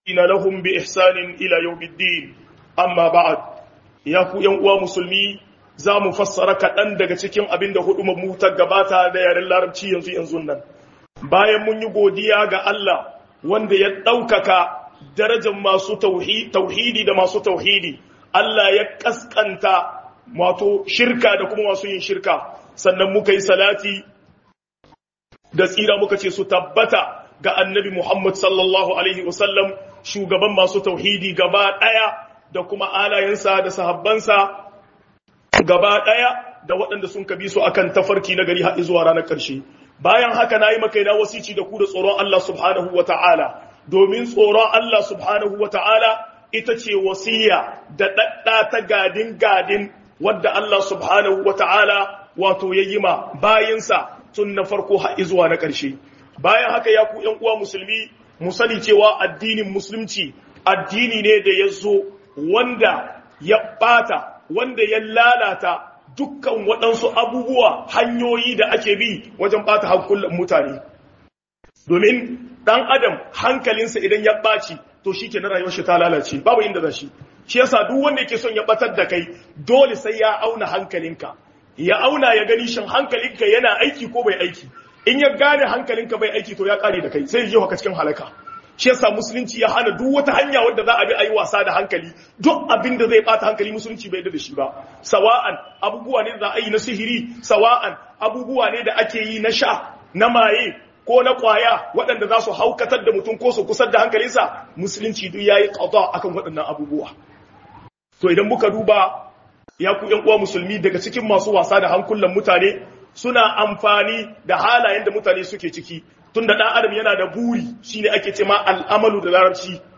KHUƊUBAR JUMA’A